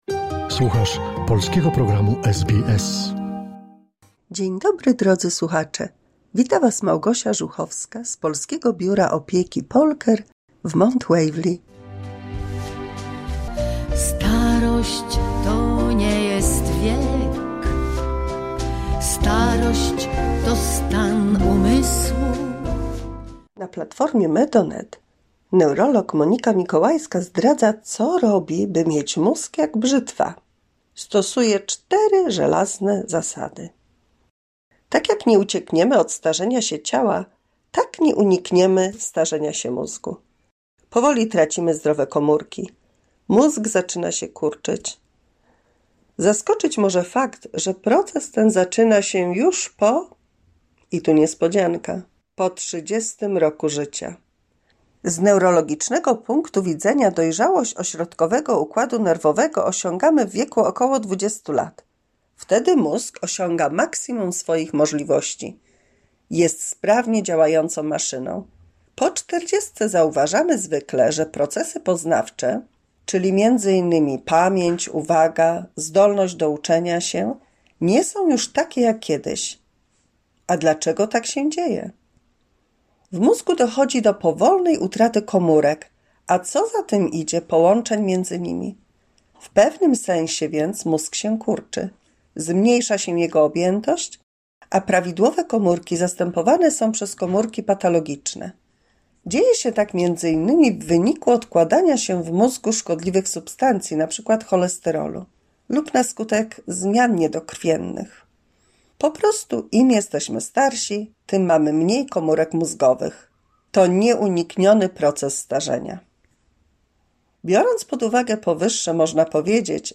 175 mini słuchowisko dla polskich seniorów